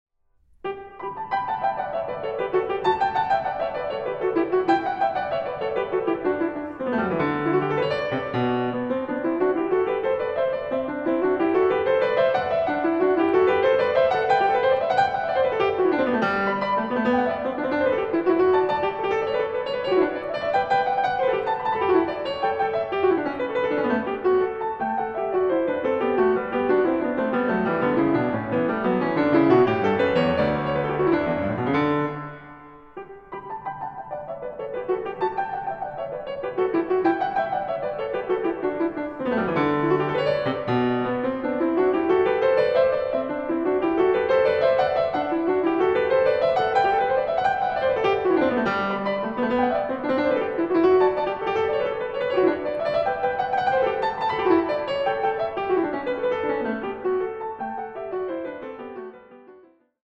Pianistin